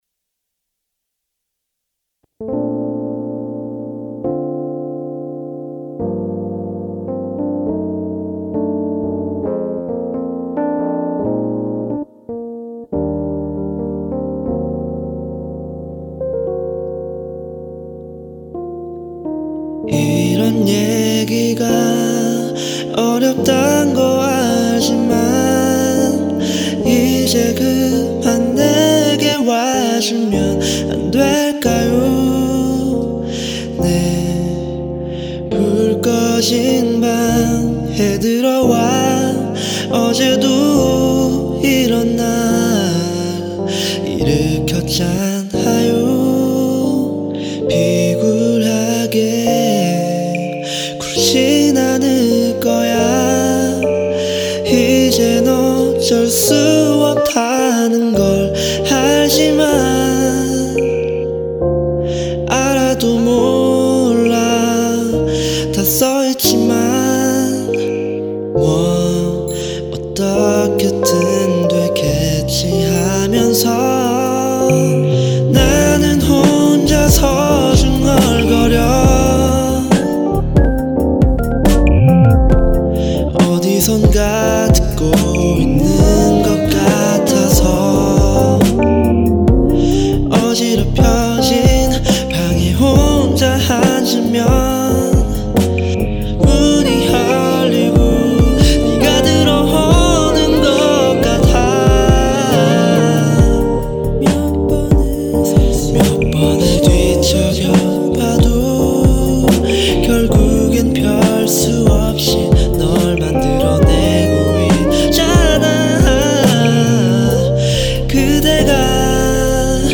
synthesizer